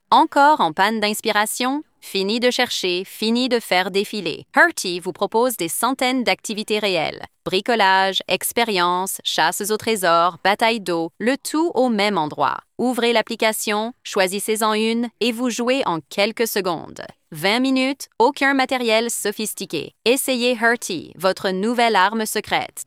dubbed-fr.mp3